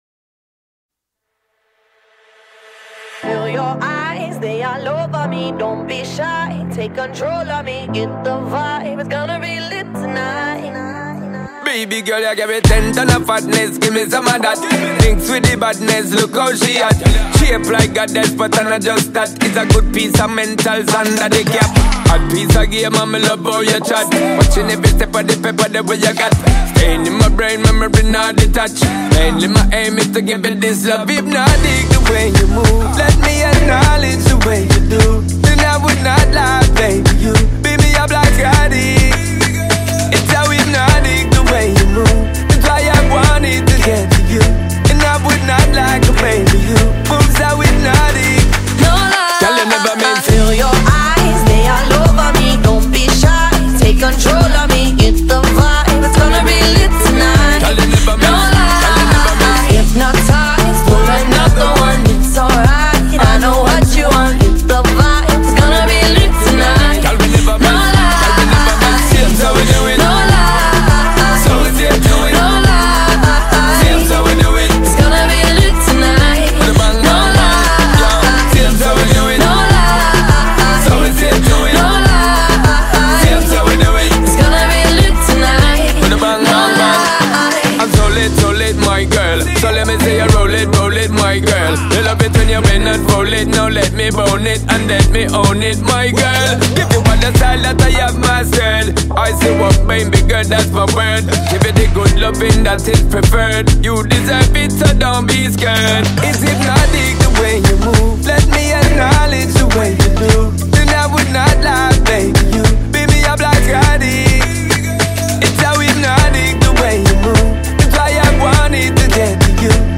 Jamaican Dancehall
melodious and electrifying hit